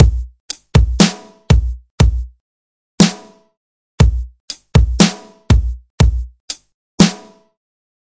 描述：Made with Electric Grand (Addictive Keys, Logic pro X).
标签： 120 bpm Hip Hop Loops Piano Loops 1.35 MB wav Key : C
声道立体声